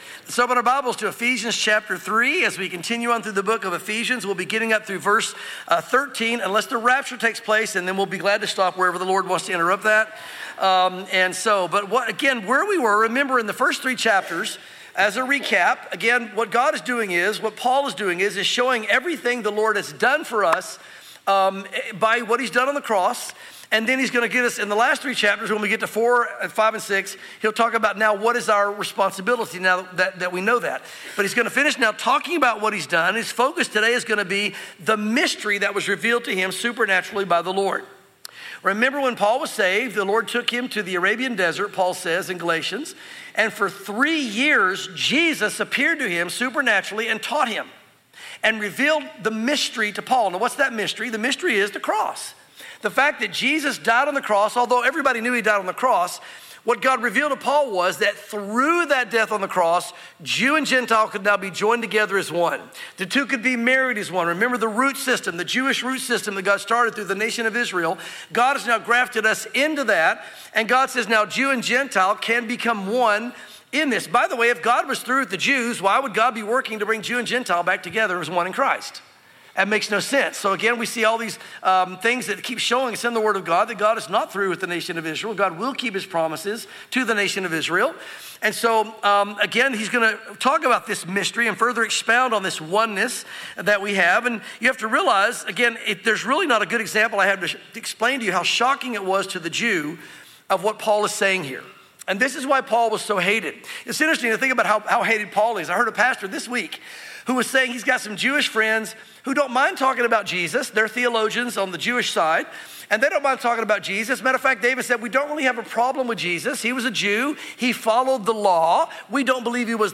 sermons Ephesians 3:1-13 | The Mystery Revealed